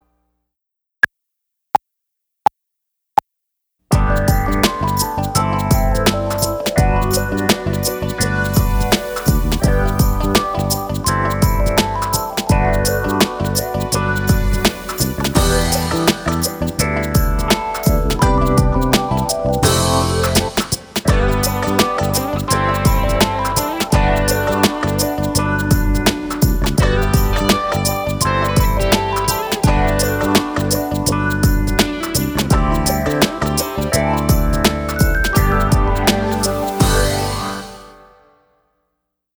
Obra Obrigatória Nível 1 -Twice Is Nice sem flauta guia
Obra-Obrigatoria-Nivel-1-Twice-Is-Nice-sem-flauta-guia.mp3